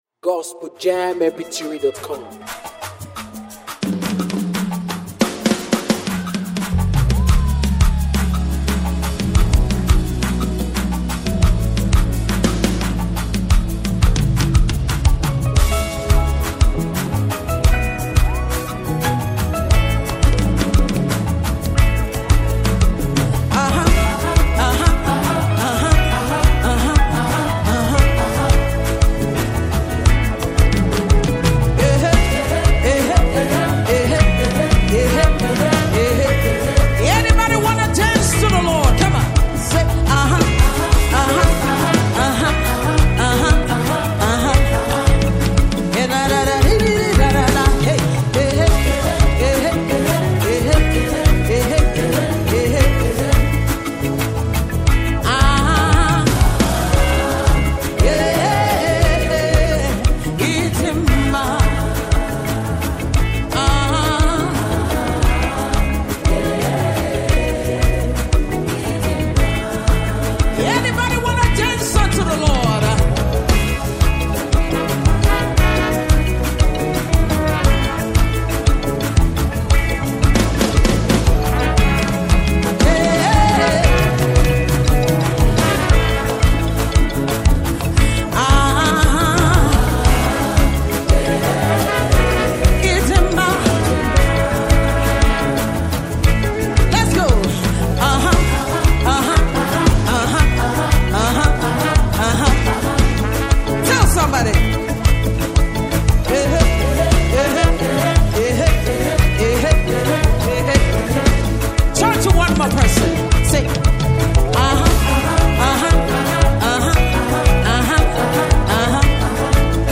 gospel worship song
With heartfelt lyrics and a soulful melody